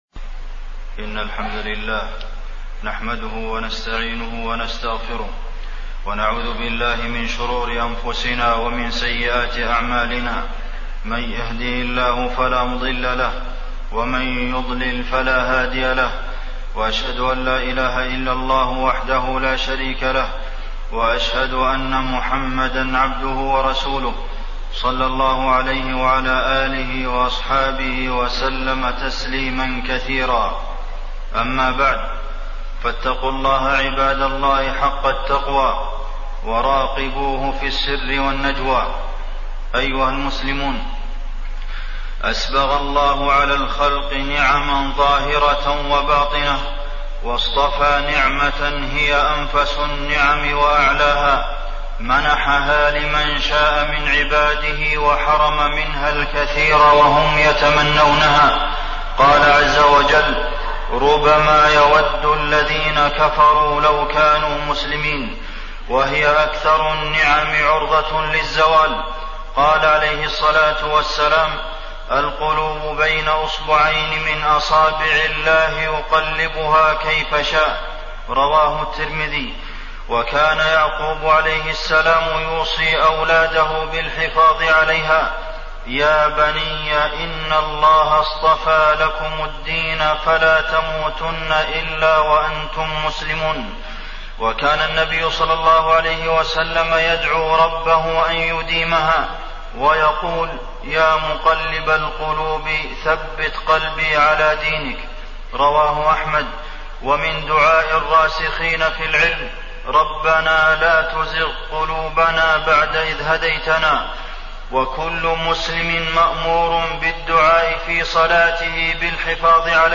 تاريخ النشر ١٧ رجب ١٤٣٠ هـ المكان: المسجد النبوي الشيخ: فضيلة الشيخ د. عبدالمحسن بن محمد القاسم فضيلة الشيخ د. عبدالمحسن بن محمد القاسم مجاهدة النفس عن الهوى